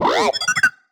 sci-fi_driod_robot_emote_25.wav